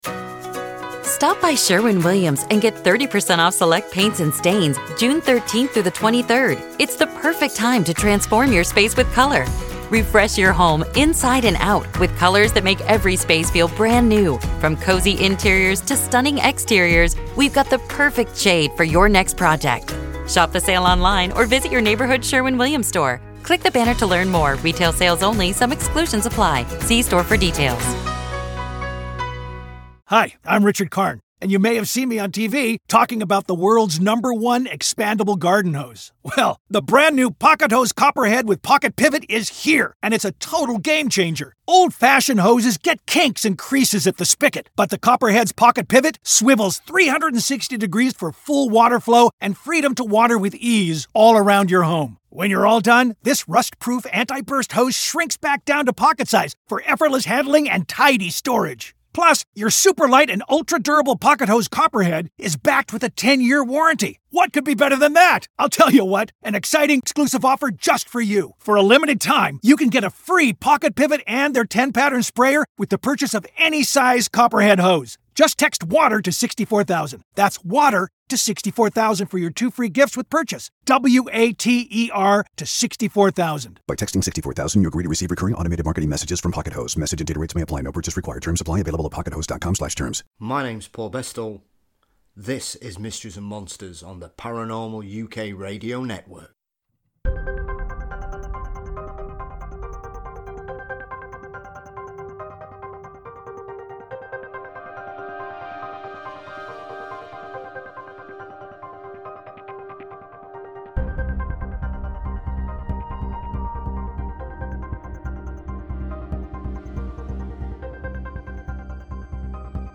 We cover both this book and touch on his first volume in a fantastic conversation that covers the whole gamut of paranormal activity.